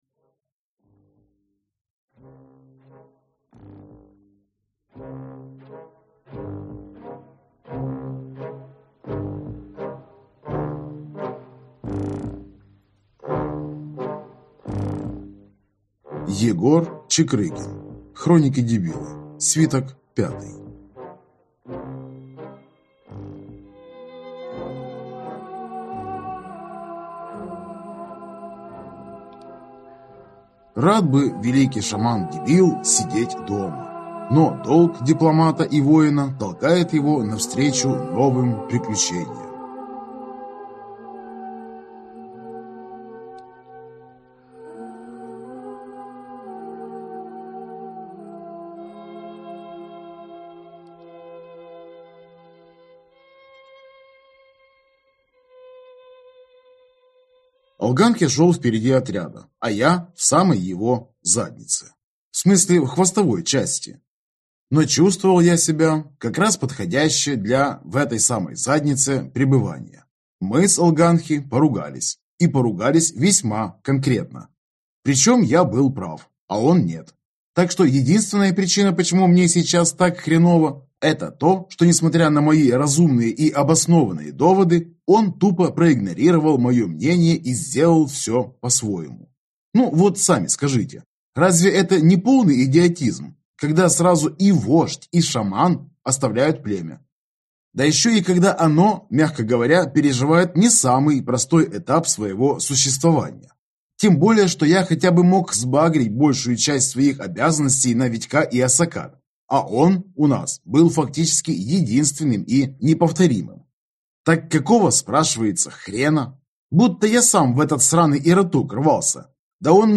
Аудиокнига Хроники Дебила. Свиток 5. У истоков Империи | Библиотека аудиокниг